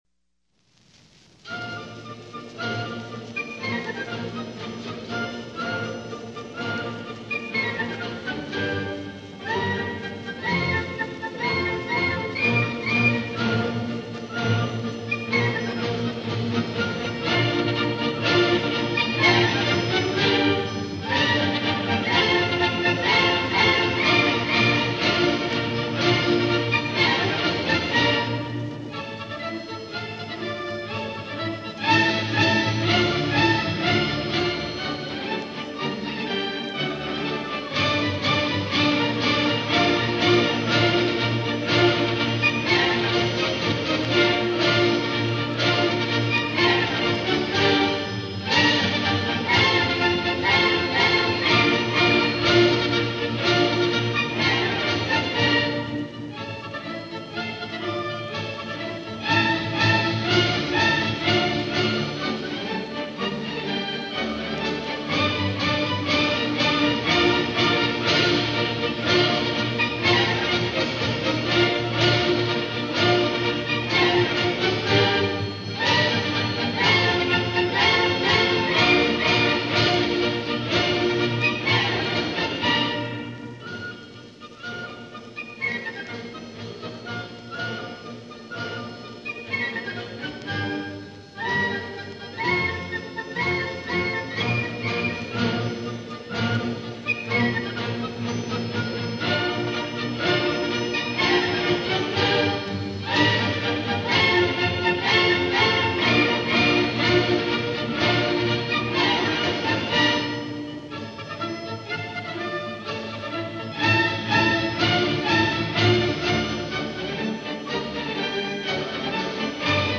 Concertgebouw Orchestra Amsterdam
Gramophone recording Turkish March